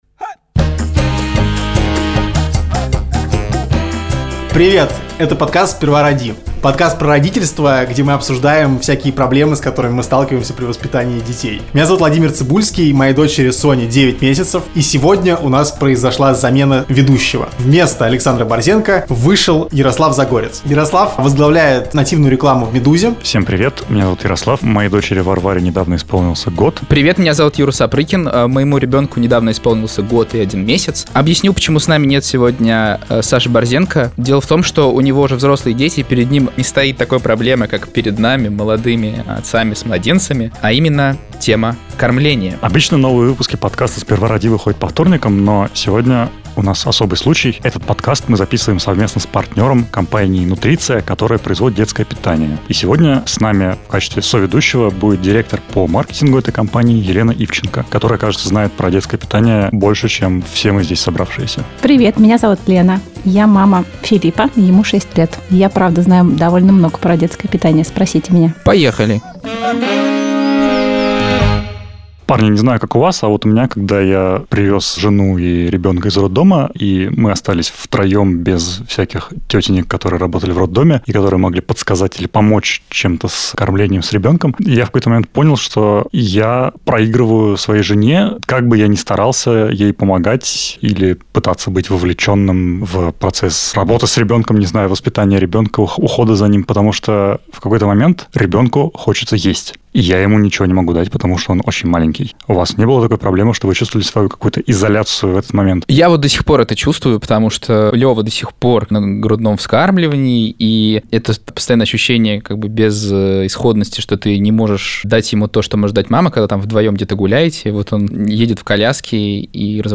В специальном партнерском выпуске ведущие говорят про детскую еду и сложности (не всегда очевидные), которые поджидали их на пути от грудного молока через кашу к хамону.